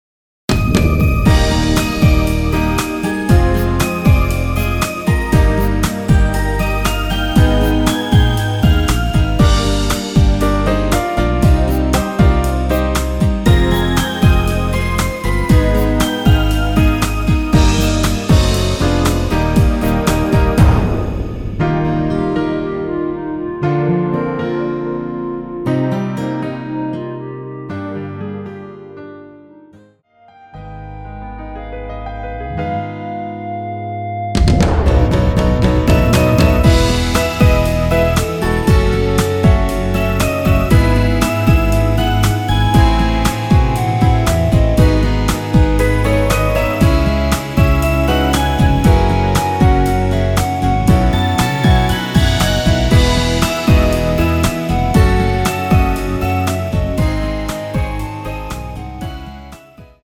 원키 멜로디포힘된 MR 입니다.(미리듣기 참조)
Eb
멜로디 굉장히 깔끔하고 좋네요~
앞부분30초, 뒷부분30초씩 편집해서 올려 드리고 있습니다.